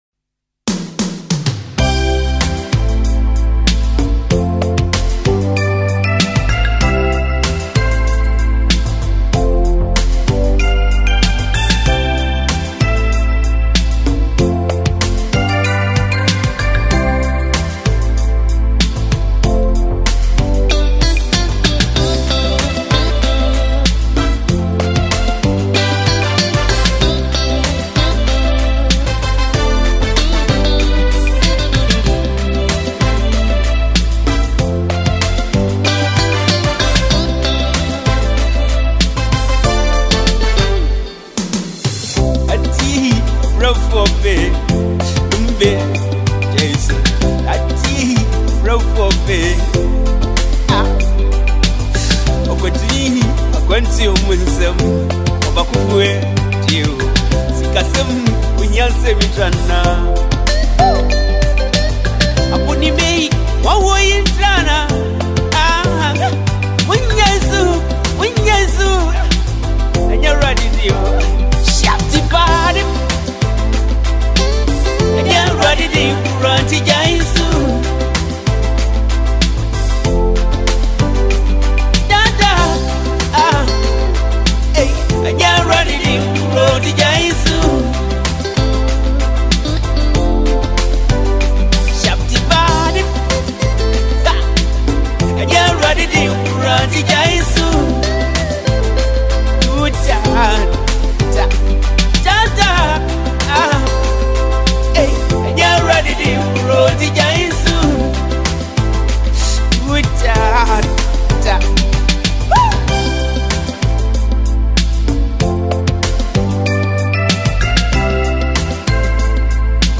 highlife
Known for his deep lyrics and soul-stirring vocals
With its rich melodies and inspirational message